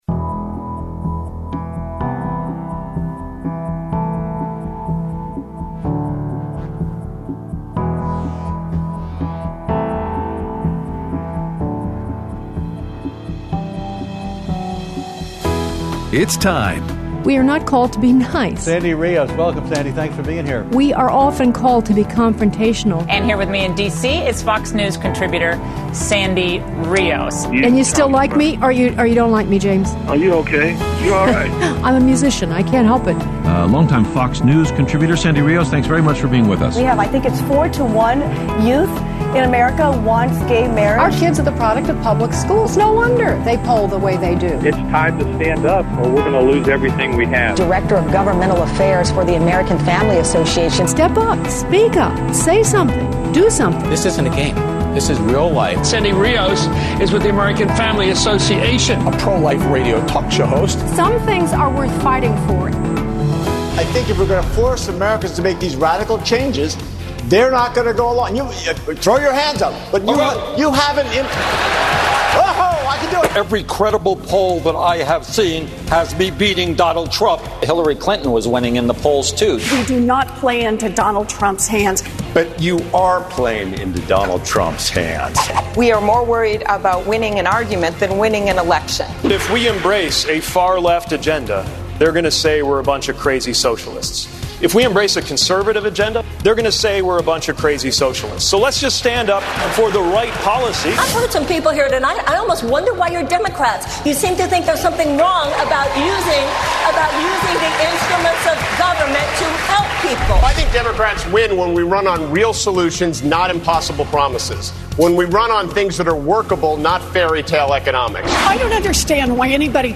Analysis of Night 1 of CNN Democratic Debate and Your Phone Calls